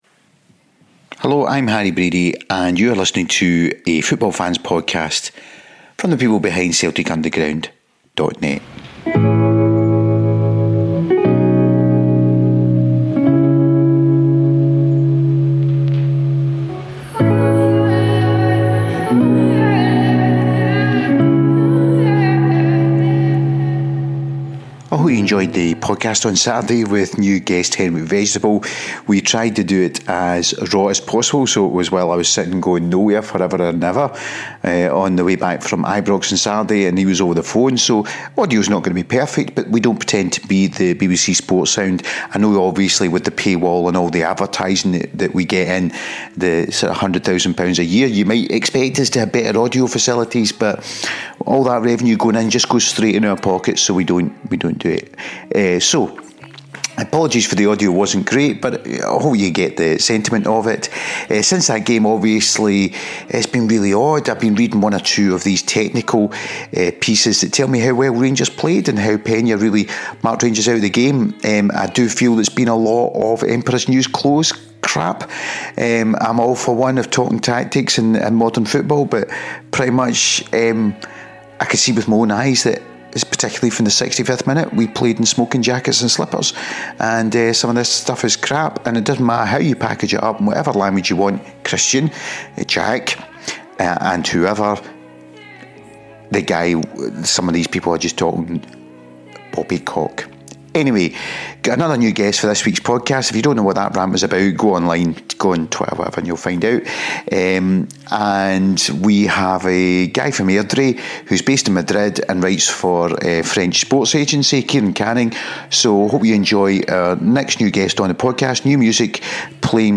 Following the comfortable 2-0 victory in our second Glasgow Derby of the season (after a more tightly contested game against Glasgow’s second club at Firhill) we now have perhaps our most important Champions League game of the season coming up. With such an important fixture I thought I’d continue our new podcast guests with a foreign based journalist, from Airdie, working for a French news agency.